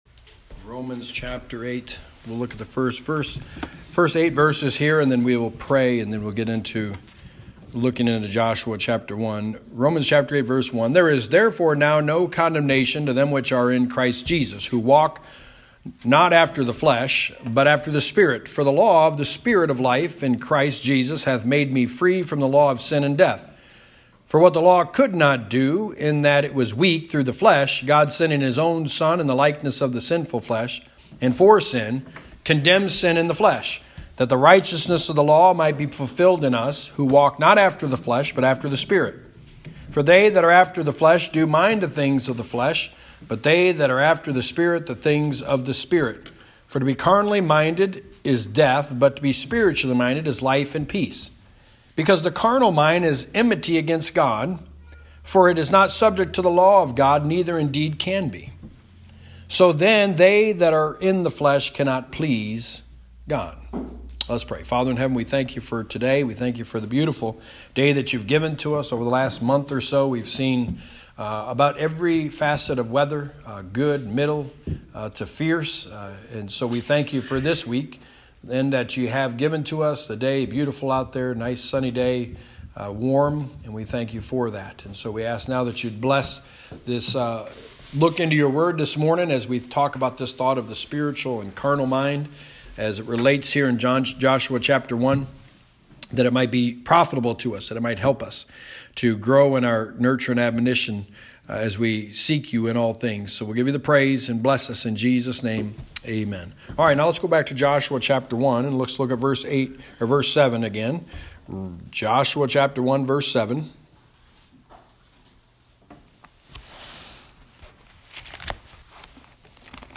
The Book of Joshua: Sermon 6 – Joshua 1:7-9
Service Type: Sunday Morning